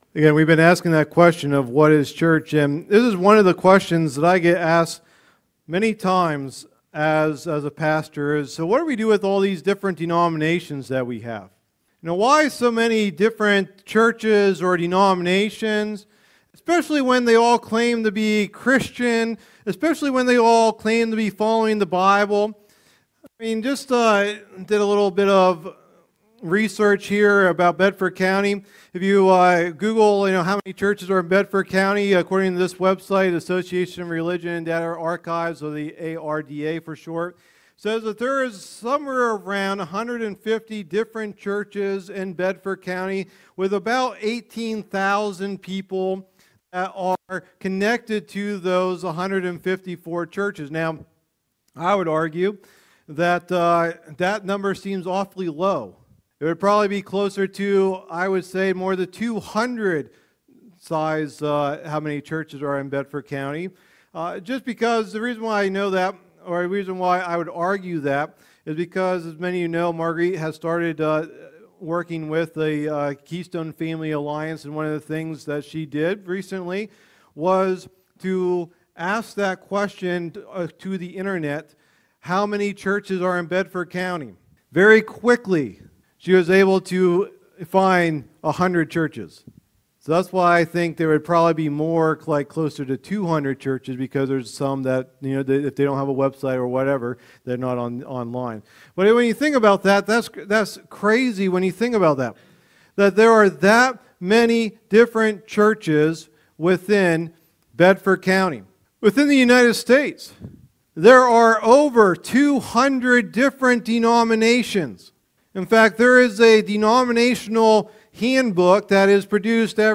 Message #16 in the "What is Church?" teaching series